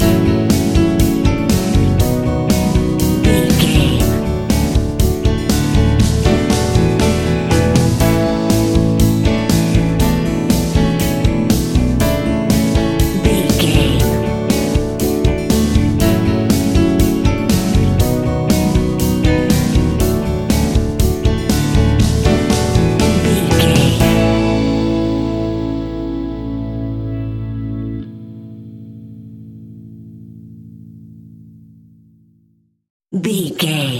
Ionian/Major
pop rock
indie pop
energetic
uplifting
instrumentals
upbeat
groovy
guitars
bass
drums
piano
organ